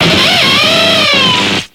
Cri de Luxray dans Pokémon X et Y.